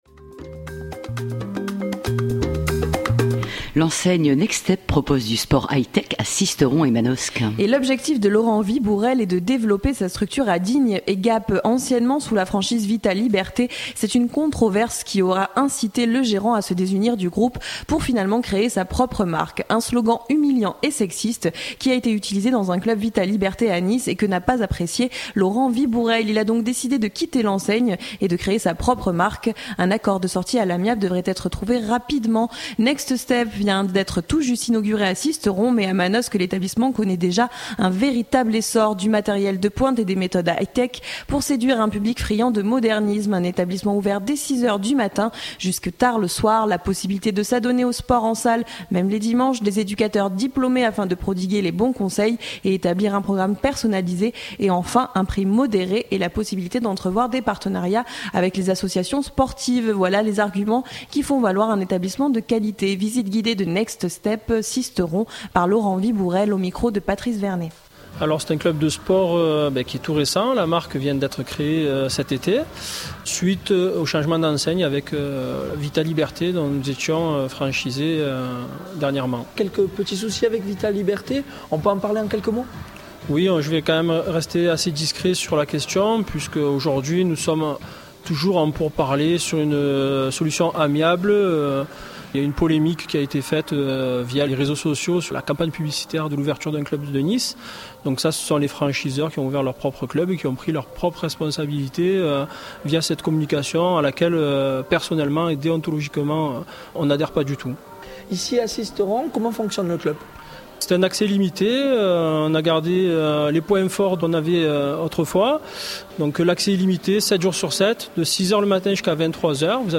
Visite guidée de Next Step Sisteron